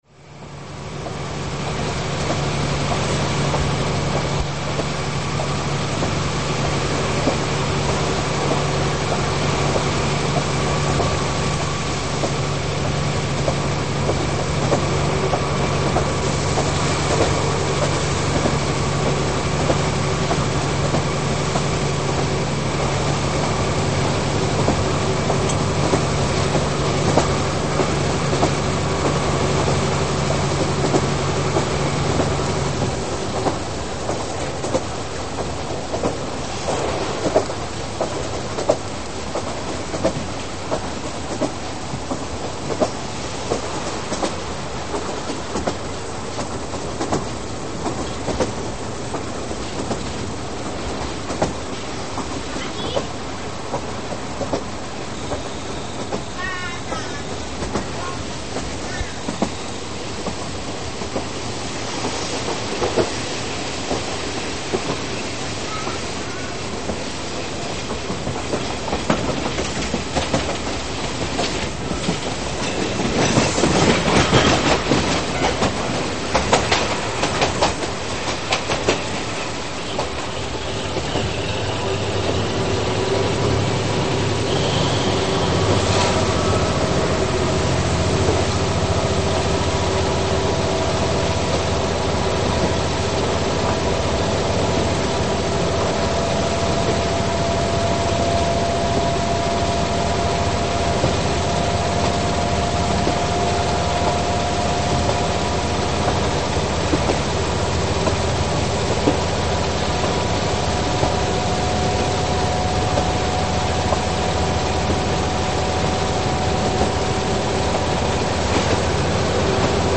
エンジン音が一番高くなる区間です。